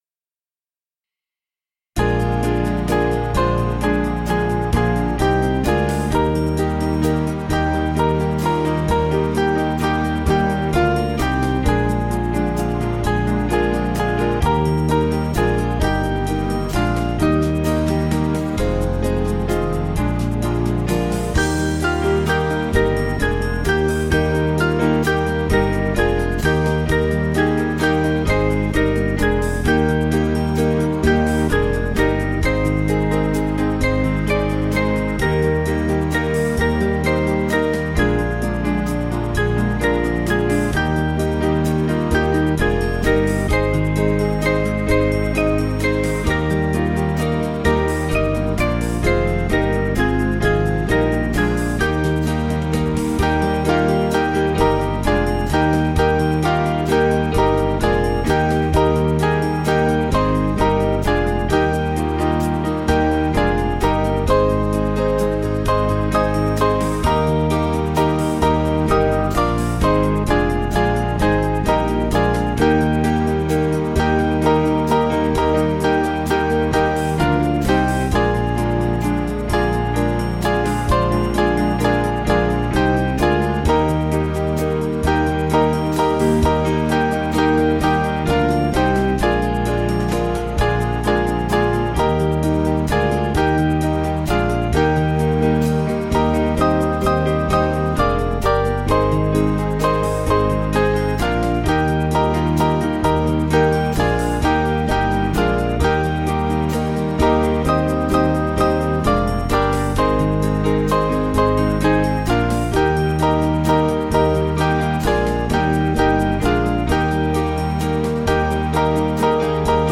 Small Band
(CM)   1/Eb 476.1kb